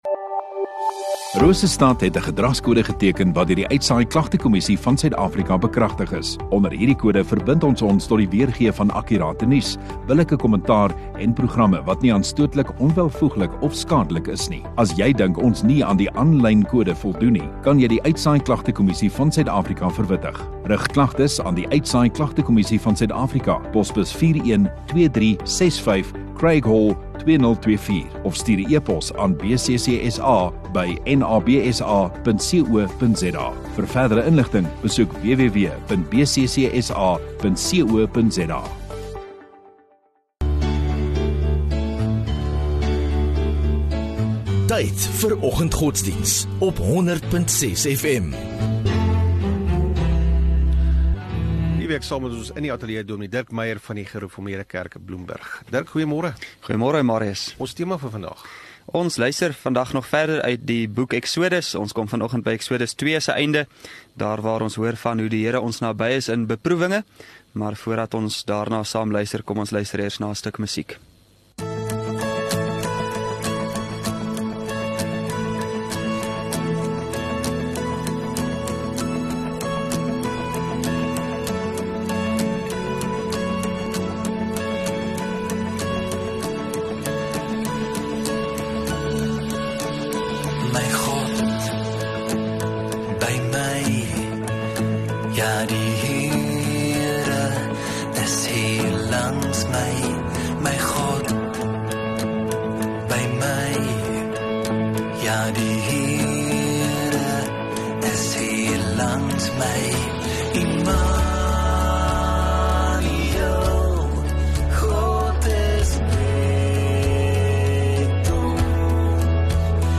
25 Sep Woensdag Oggenddiens